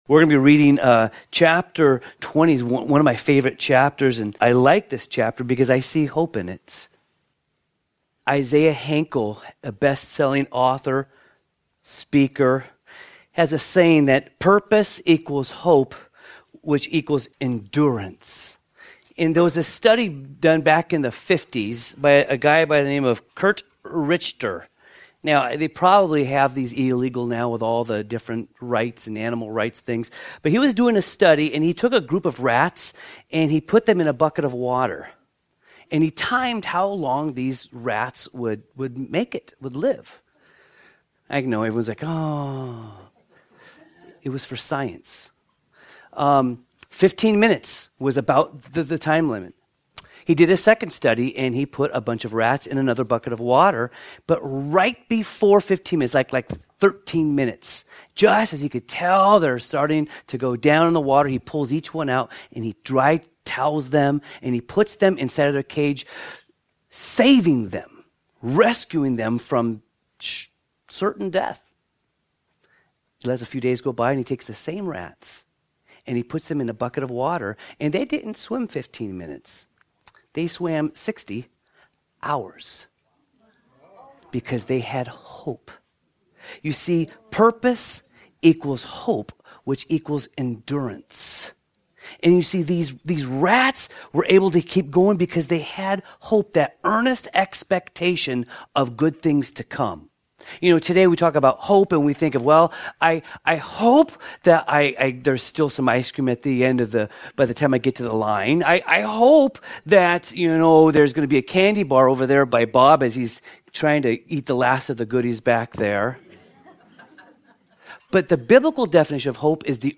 Sermons | Calvary Chapel Lighthouse Fellowship